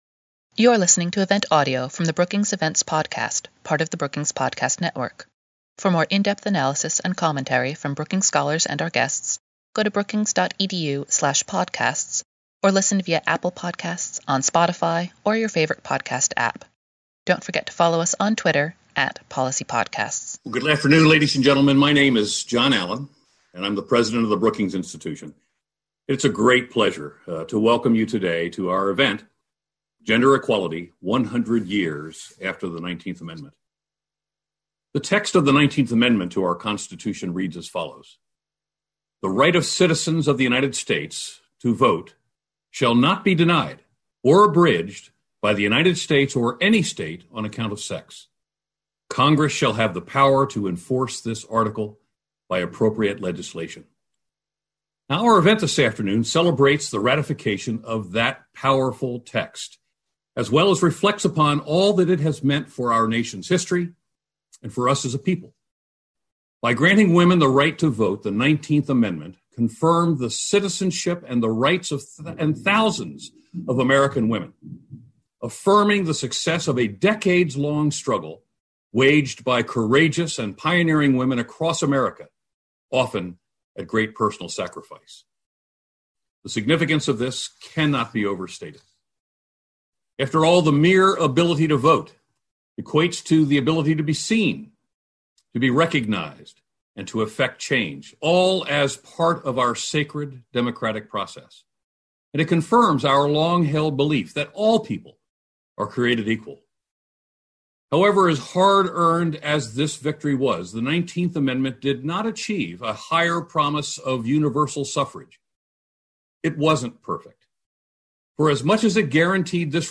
On August 24, as part of 19A: The Brookings Gender Equality Series, Brookings hosted a webinar to examine the state of gender equality today and what needs to be done to achieve full equality for women in our society.